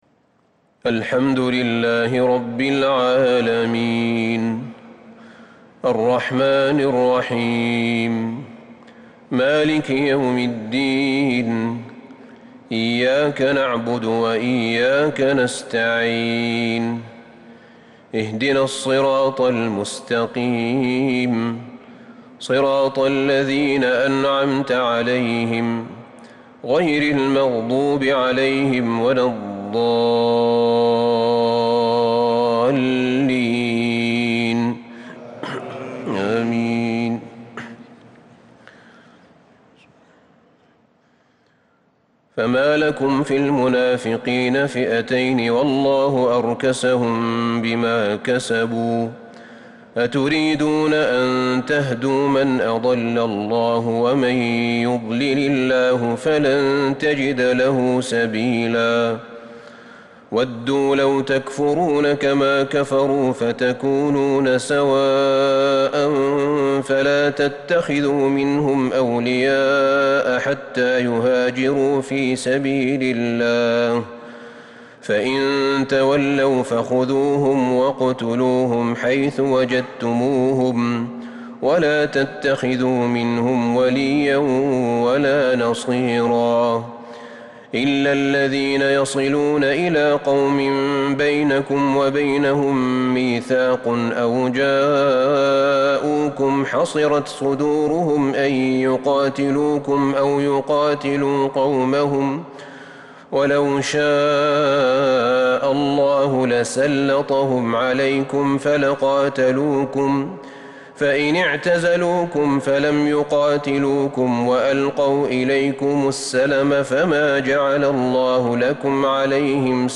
تراويح ليلة 7 رمضان 1442هـ من سورة النساء {88-134} Taraweeh 7st night Ramadan 1442H Surah An-Nisaa > تراويح الحرم النبوي عام 1442 🕌 > التراويح - تلاوات الحرمين